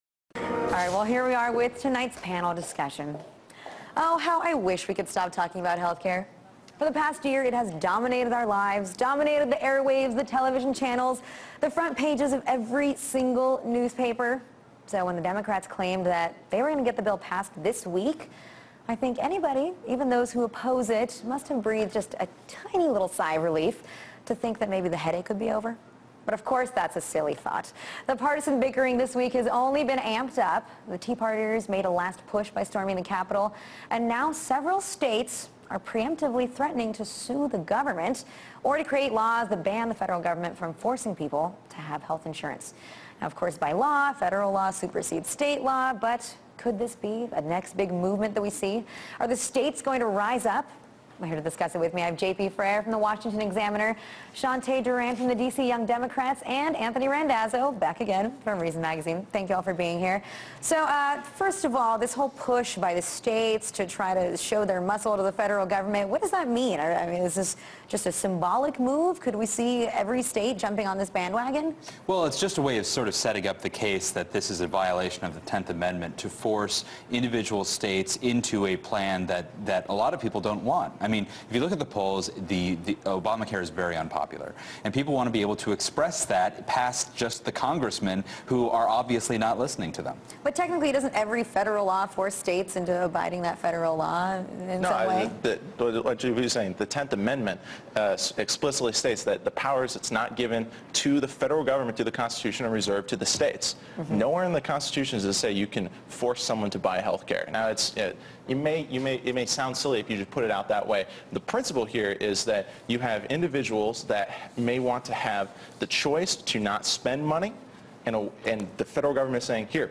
joins a panel to discuss healthcare reform and the latest conflict between the Federal government and States rights